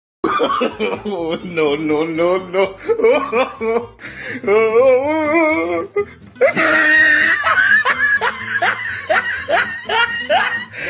oh no no no laugh sound effect
Sound Meme
oh_no_no_no_laugh_sound_effect.mp3